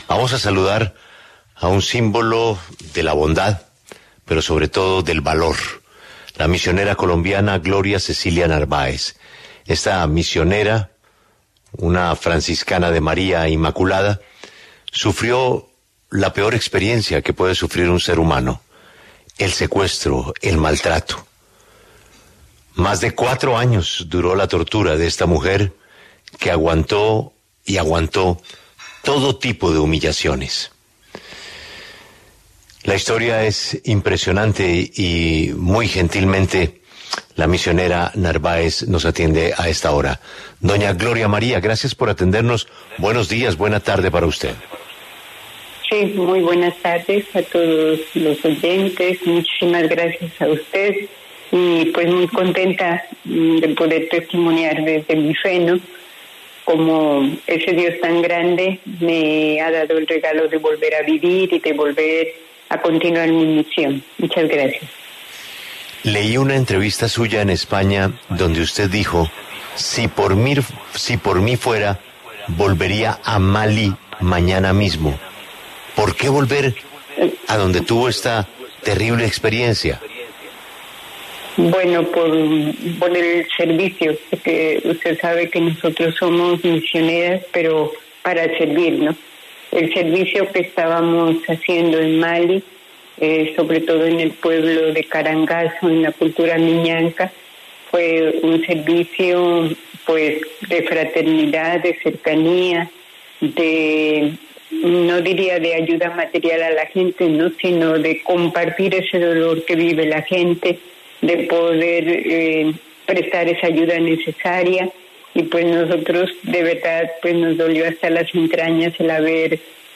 conversó en La W sobre sus misiones.